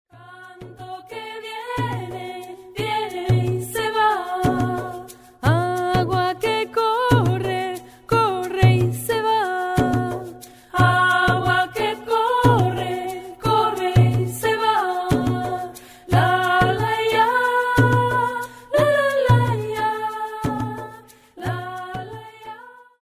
cello, voice, and percussion
transverse flute and percussion